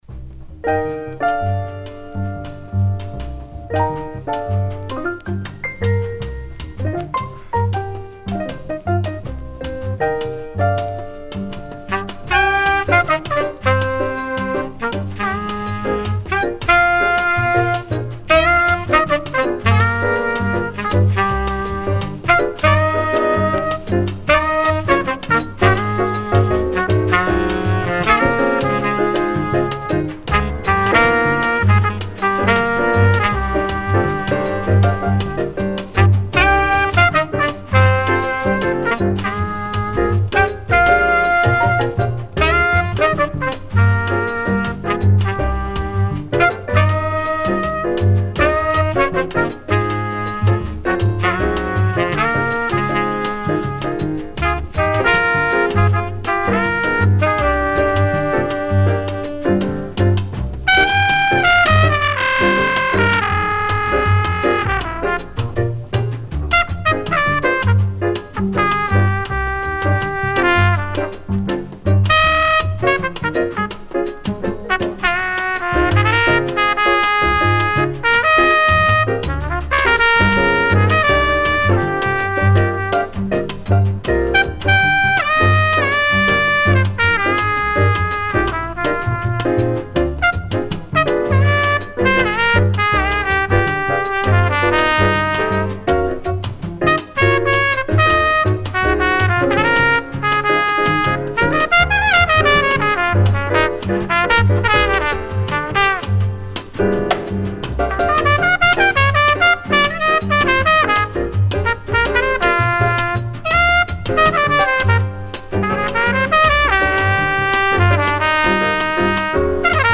mono pressings ca. 1966